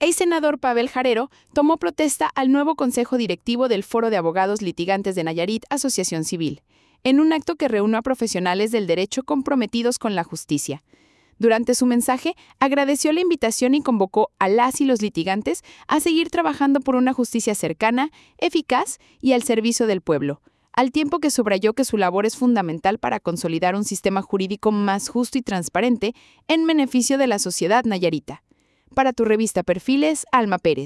COMENTARIO EDITORIAL